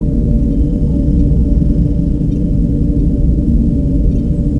vehiclebay.ogg